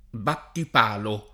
battipalo [ battip # lo ] s. m.; pl. -li